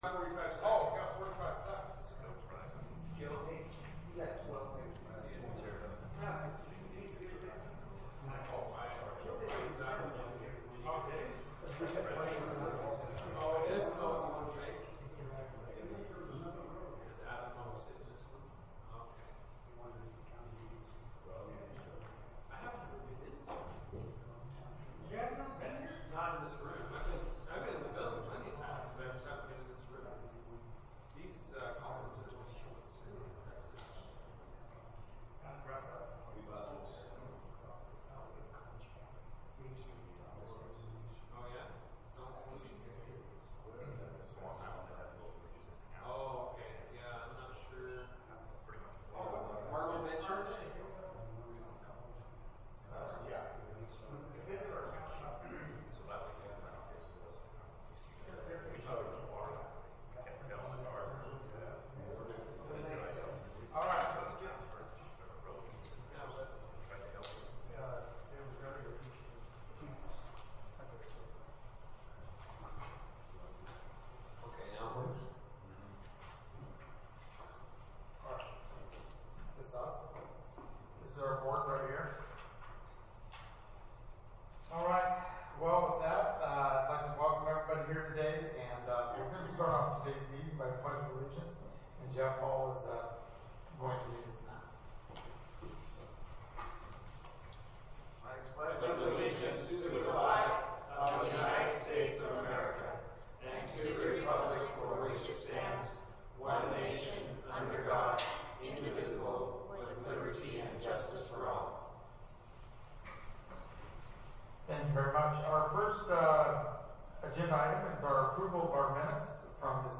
Meeting